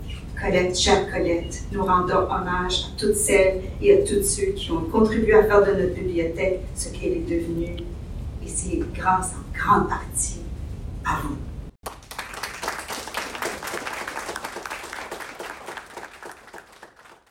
Plusieurs personnalités politiques et communautaires de la Ville de Brossard étaient présentes ou ont fait un message pour souligner cette inauguration.
La députée provinciale de La Pinière Linda Caron et la mairesse de Brossard Doreen Assaad était sur place pour remercier les bénévoles.